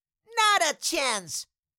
Cartoon Little Child, Voice, Not A Chance Sound Effect Download | Gfx Sounds
Cartoon-little-child-voice-not-a-chance.mp3